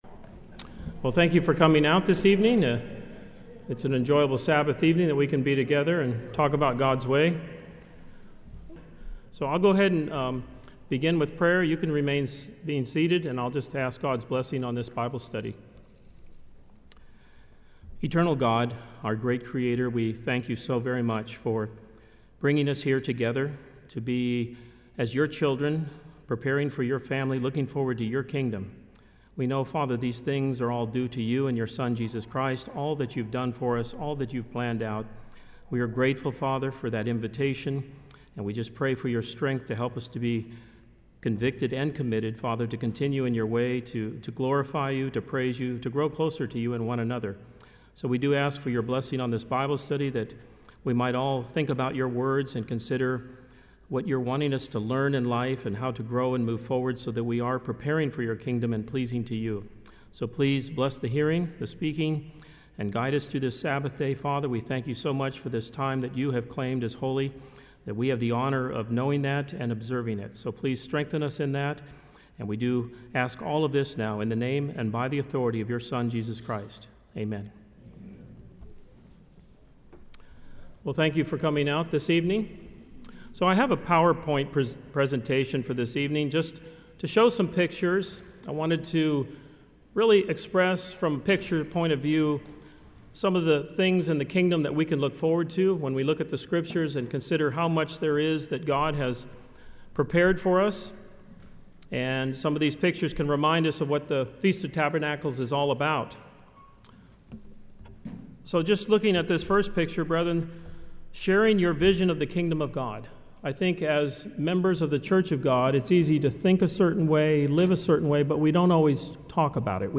This sermon was given at the Jekyll Island, Georgia 2019 Feast site.